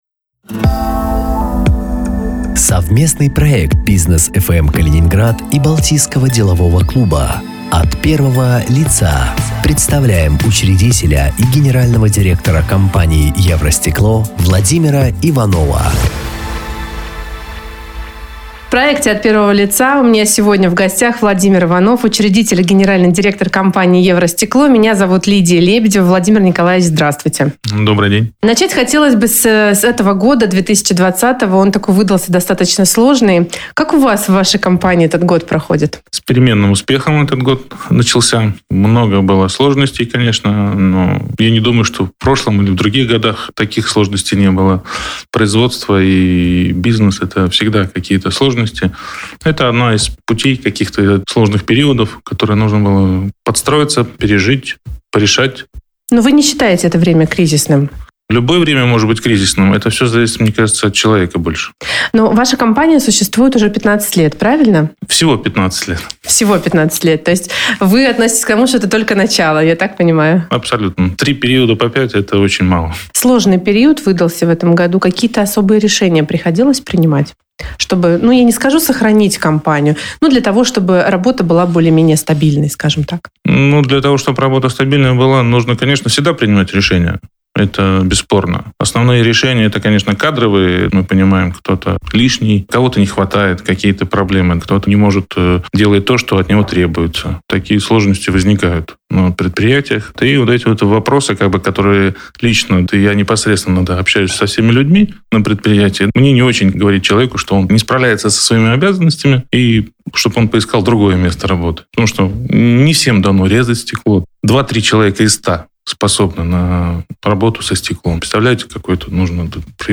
Совместный проект радиостанции БизнесFM-Калининград и Балтийского делового клуба. Интервью с главами калининградских компаний, в которых они рассказывают о себе, бизнесе и деловой среде нашего города.